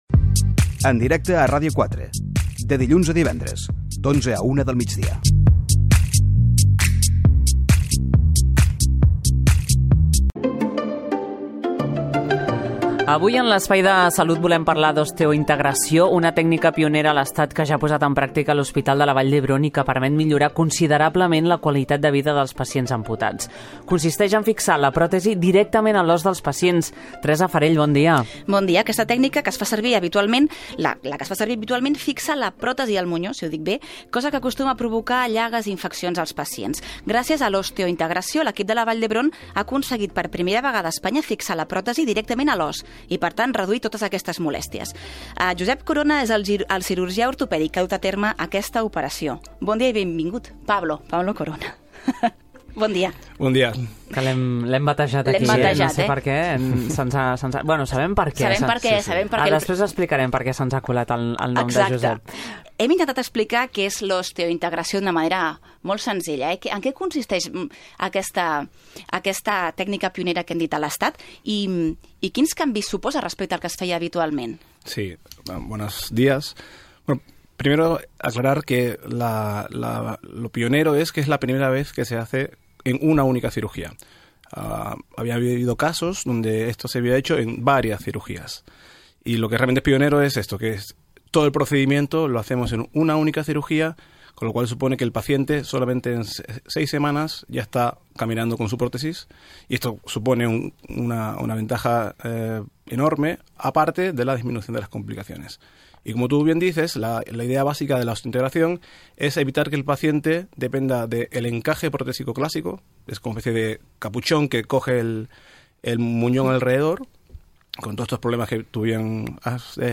Entrevistan